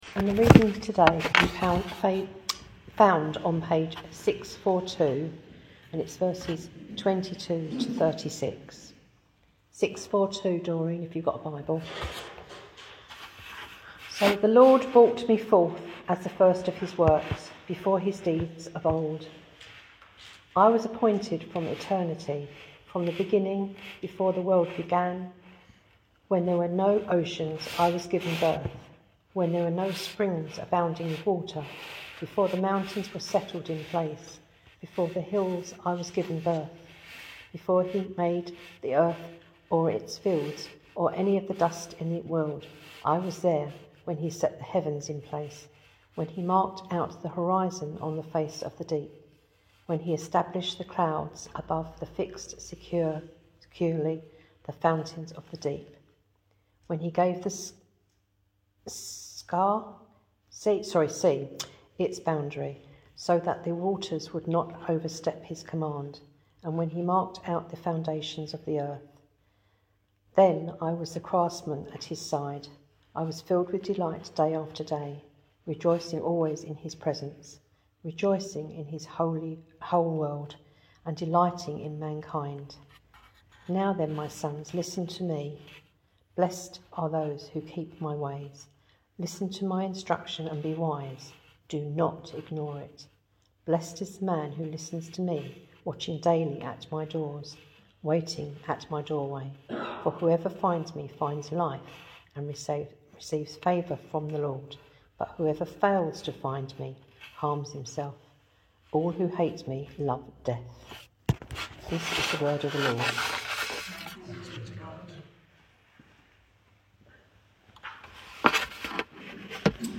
Proverbs Passage: Proverbs 8:22-36 Service Type: Thursday 9.30am Topics